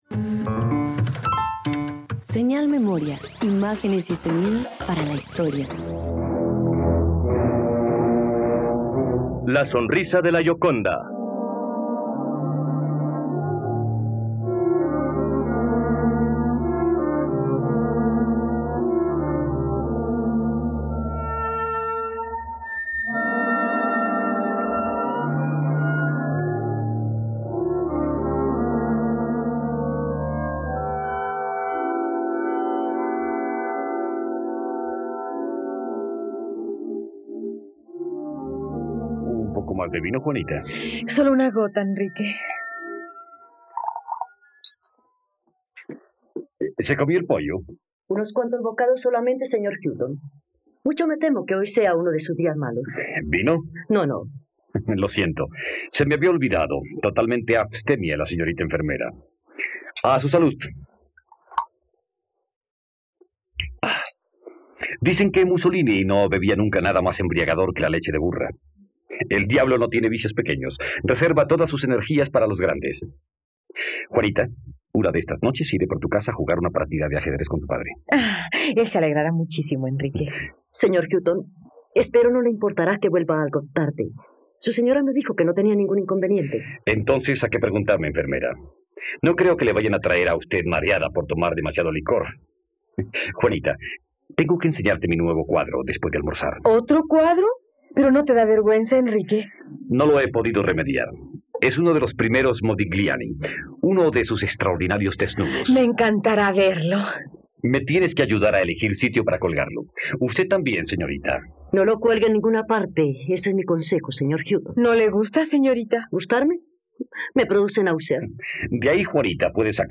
..Radioteatro. Escucha la adaptación radiofonica de la obra ‘La sonrisa de la Gioconda’ del escritor y filósofo británico Aldous Huxley.